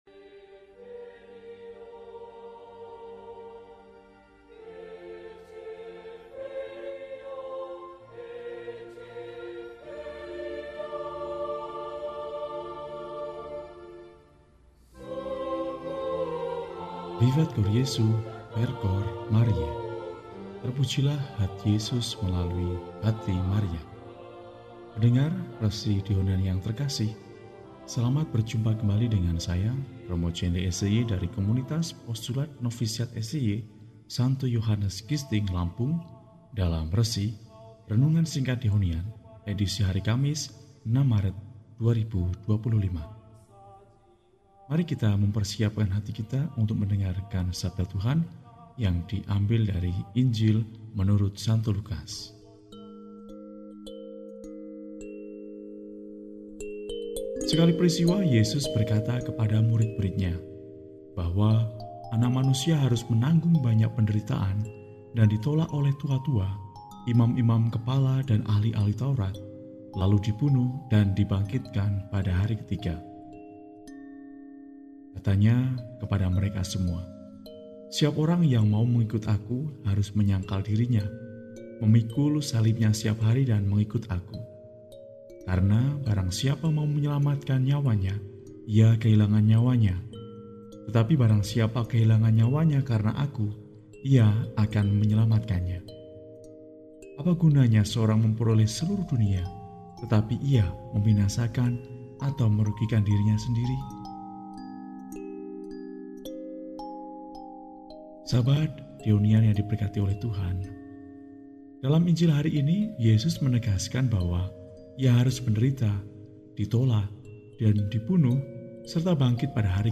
Kamis, 06 Maret 2025 – Hari Kamis sesudah Rabu Abu – RESI (Renungan Singkat) DEHONIAN